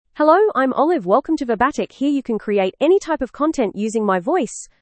Olive — Female English (Australia) AI Voice | TTS, Voice Cloning & Video | Verbatik AI
FemaleEnglish (Australia)
Olive is a female AI voice for English (Australia).
Voice sample
Female